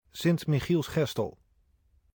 Sint-Michielsgestel (Dutch pronunciation: [ˈsɪnt miˌxilsˈxɛstəl]